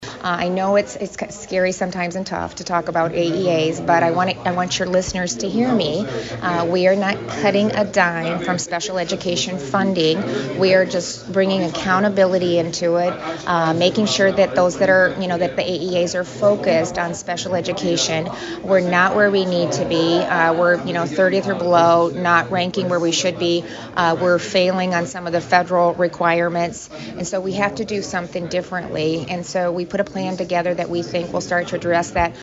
Iowa Governor Kim Reynolds tells KSOM/KS95 News she is excited about her educational package.